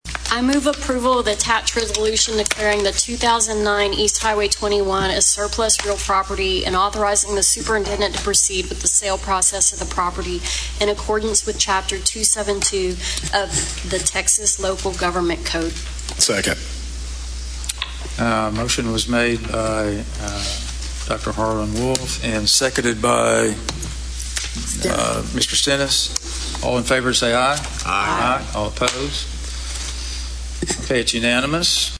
Click below to hear the motion at the December 1, 2025 Bryan ISD school board meeting approving the sale of district land and a warehouse building: